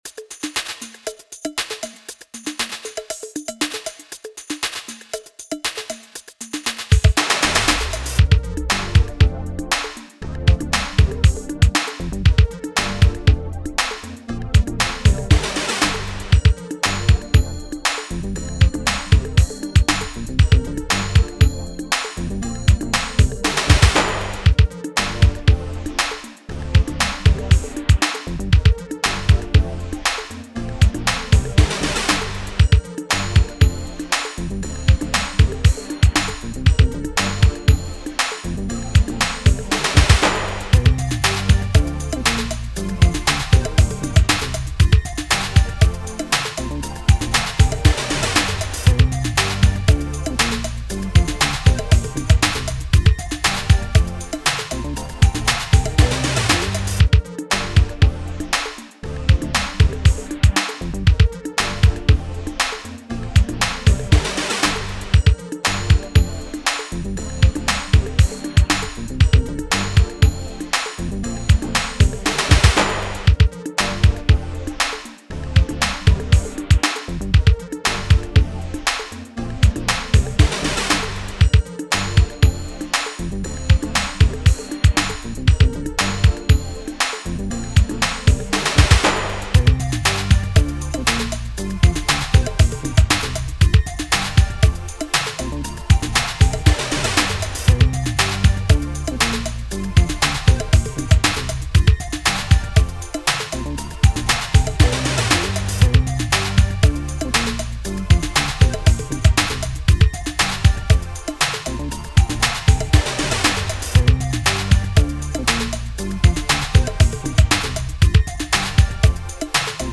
R&B, 80s, Dance, 90s
Bbmaj